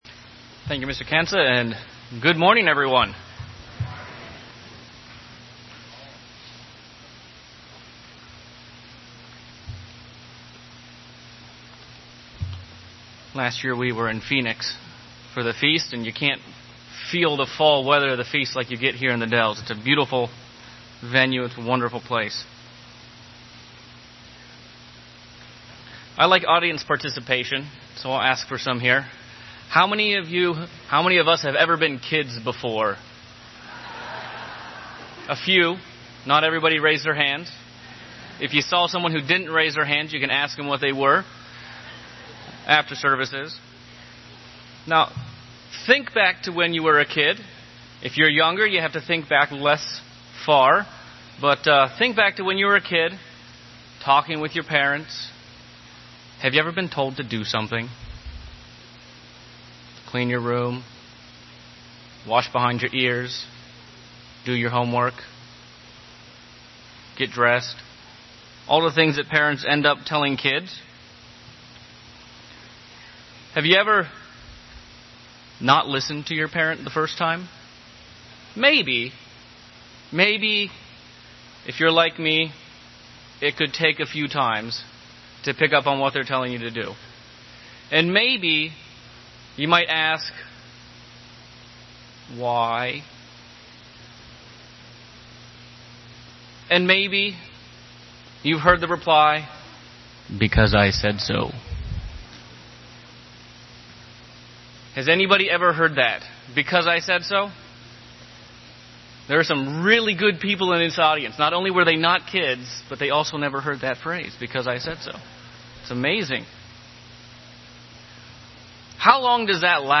This sermon was given at the Wisconsin Dells, Wisconsin 2017 Feast site.